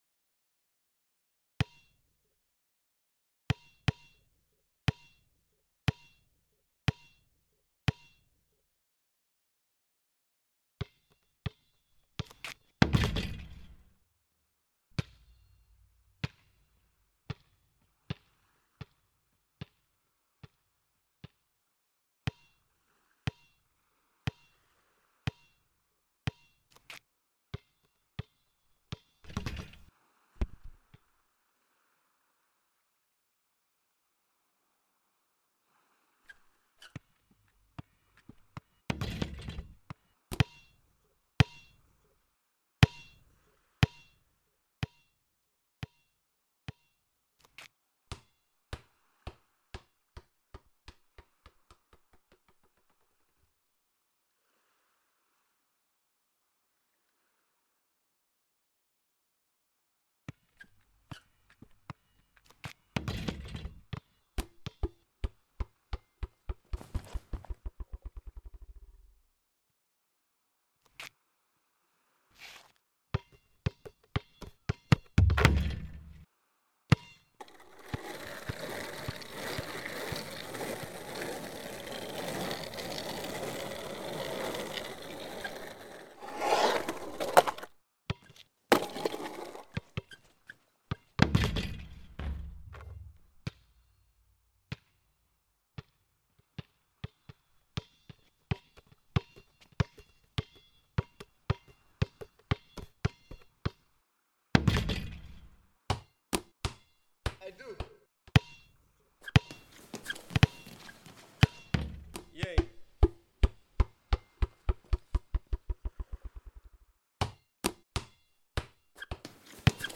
Listen to the full stereo version of the soundscape
It’s 5am. Brighton beach. You are sitting on a low wall beside the basketball court. Dawn approaches.
Now, there is no one else around except the odd seagull.